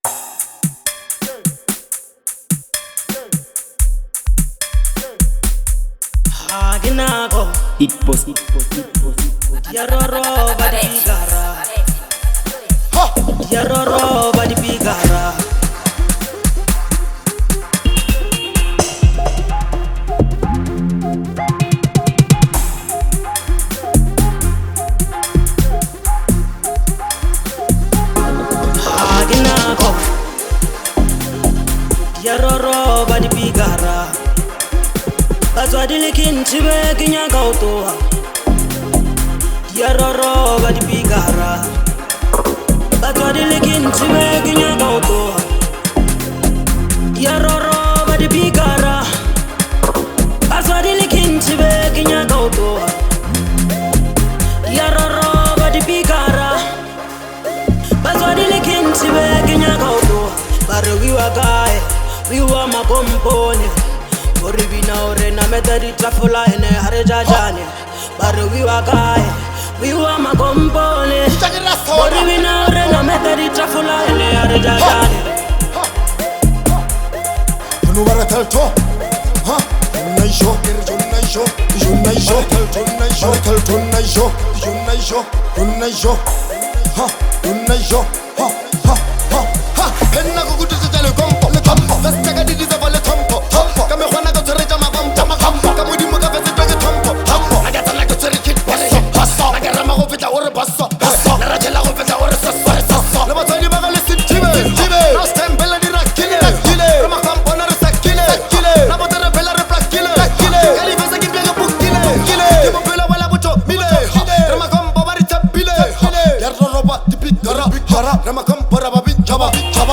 infectious groove and Lekompo melodies
Lekompo